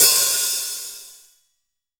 Hat open sizz3.WAV